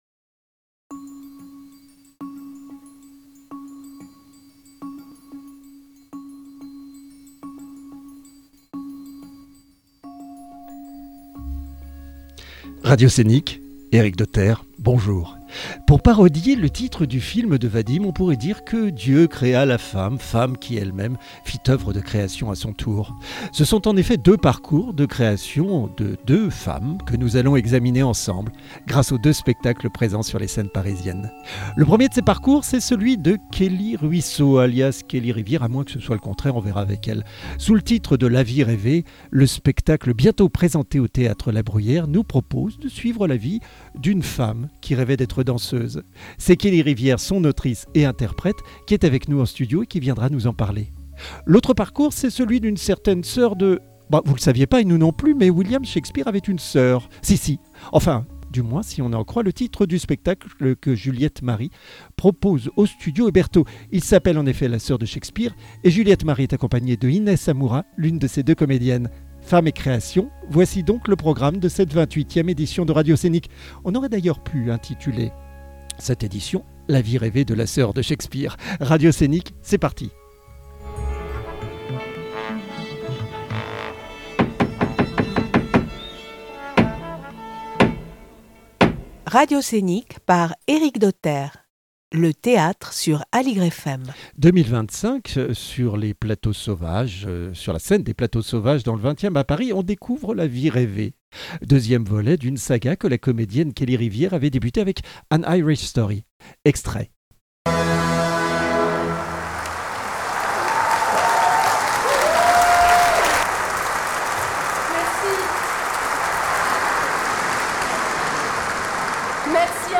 Le 27 avril à 10h, Radioscénic invite des femmes créatrices et créatives.